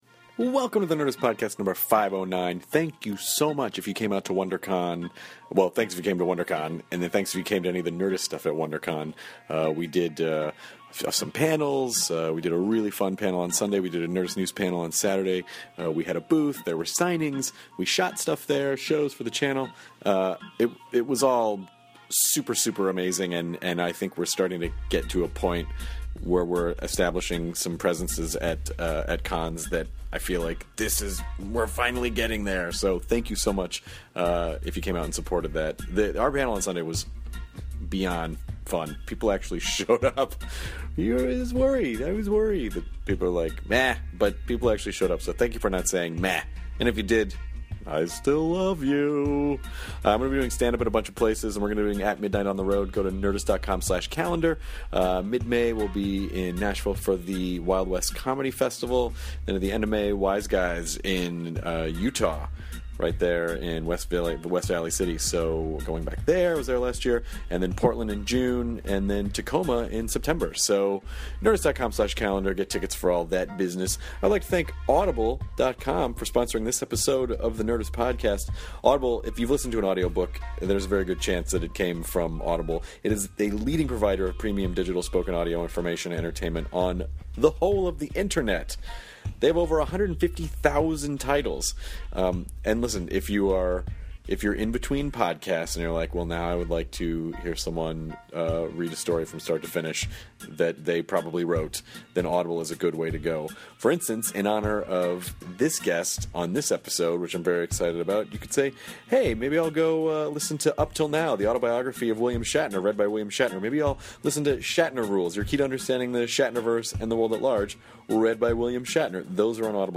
The legendary William Shatner chats with Chris and Jonah about his upcoming show Shatner’s World in movie theaters around the country, his love of making people laugh and his thoughts on the future!